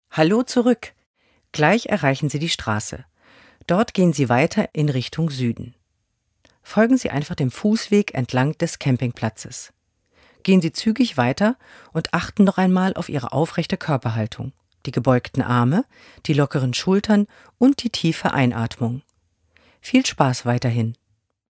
Audioguide
Verantwortlich für die Tonaufnahmen: Tonstudio an der Hochschule Stralsund.